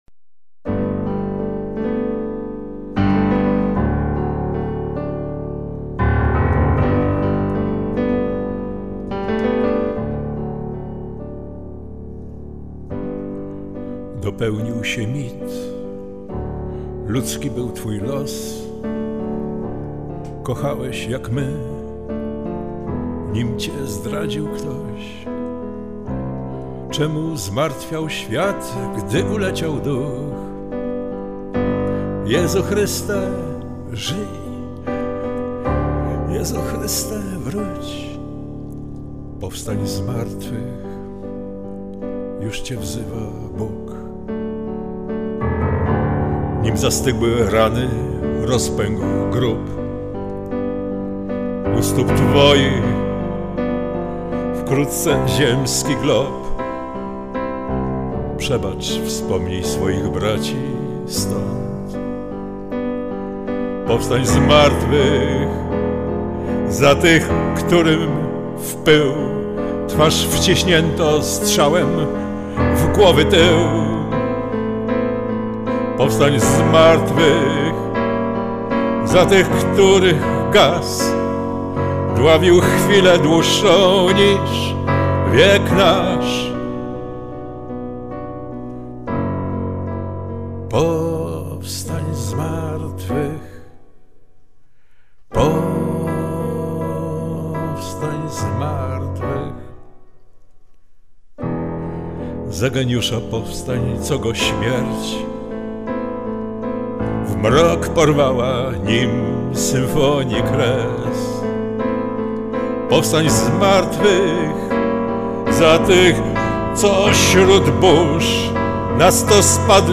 Wstałeś z martwych - śpiewa